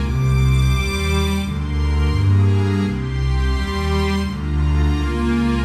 AM_80sOrch_85-C.wav